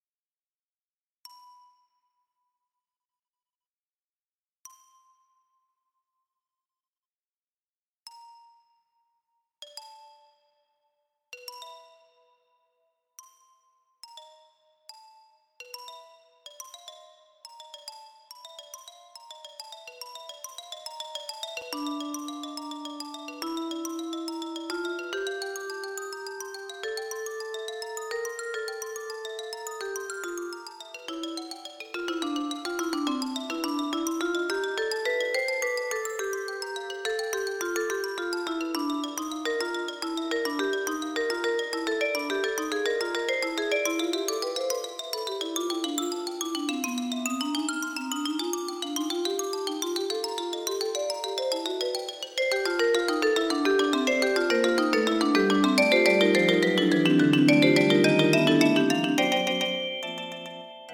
for Percussion Trio
Xylophone, Vibraphone and Marimba (4 1/3 octave)